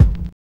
VINYL 3.wav